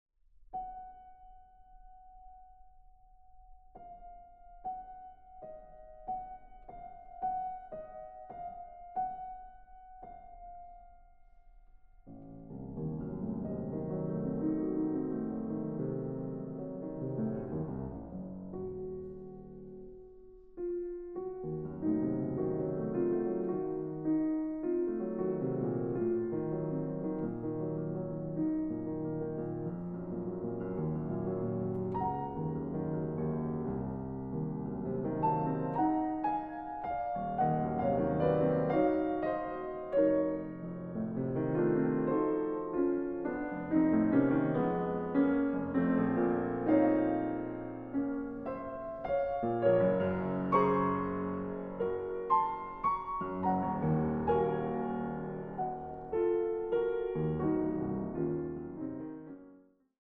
Piano
Intermezzo. Andante, largo e mesto